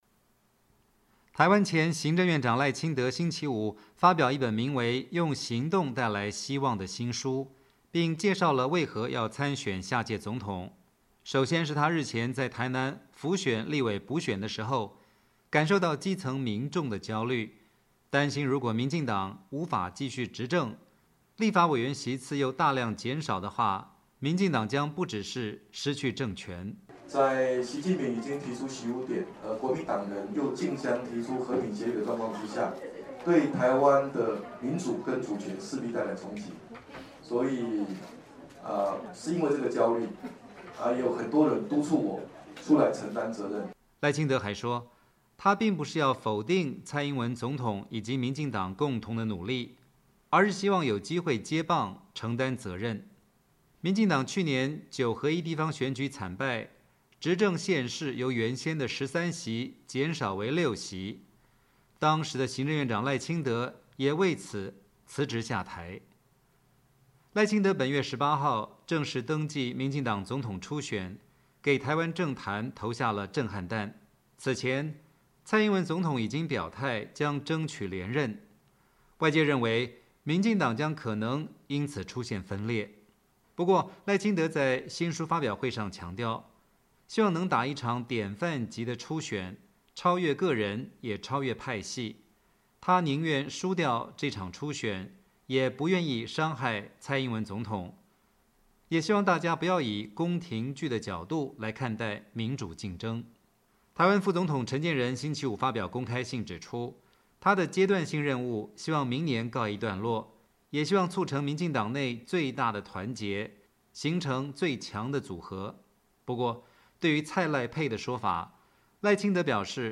台湾前行政院长赖清德在新书发表会上讲话